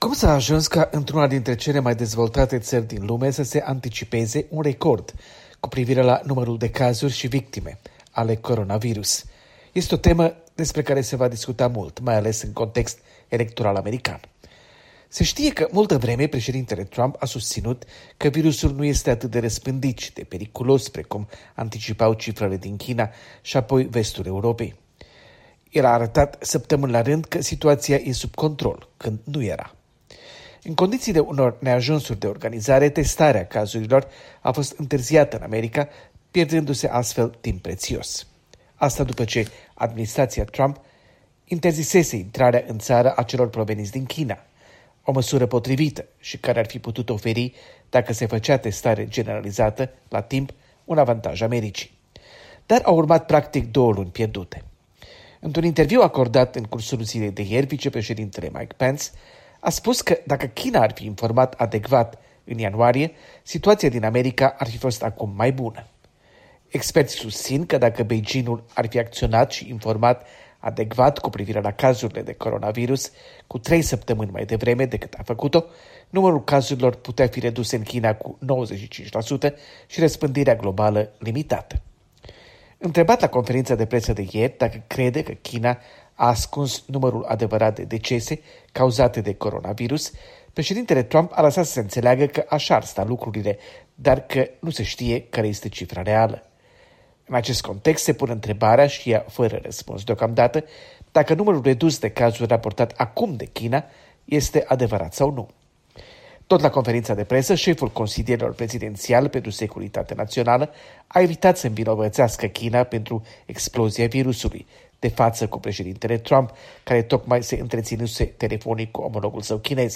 Relatre de la Washington: pandemia de coronavirus